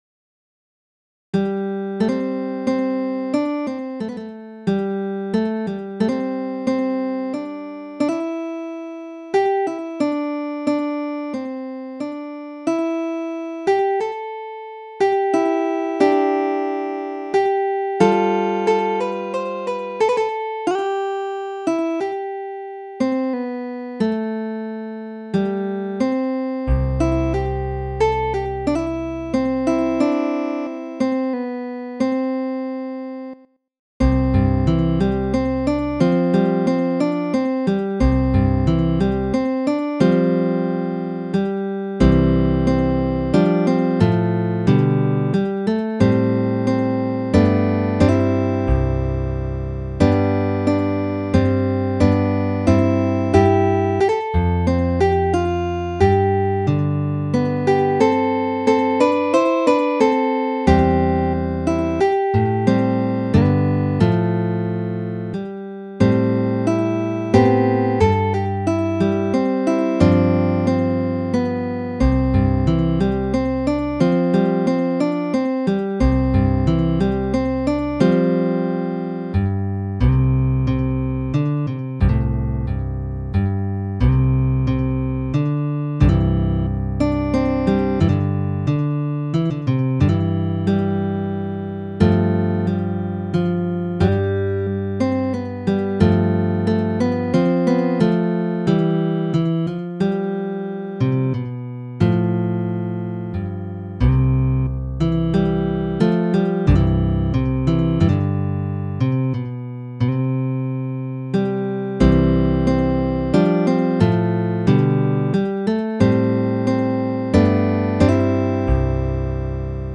lush and evocative CGDGAD guitar tuning
as fingerstyle arrangements.